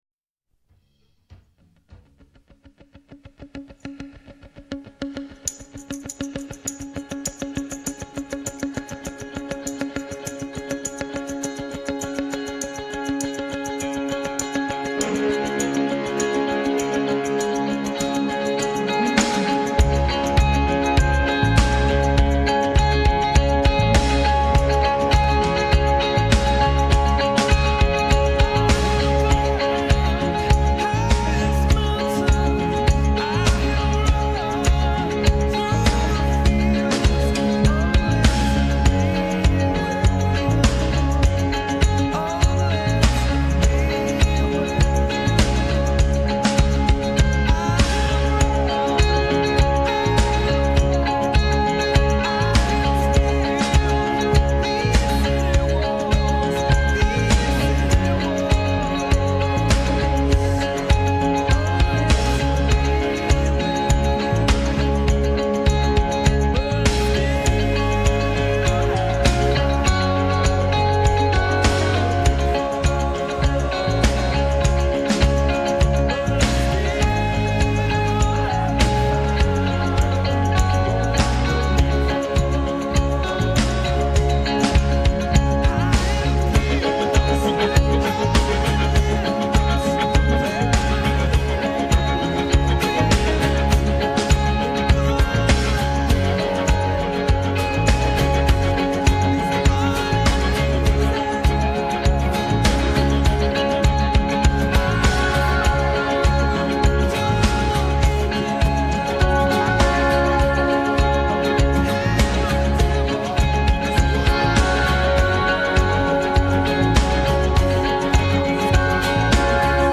(Karaoke Version)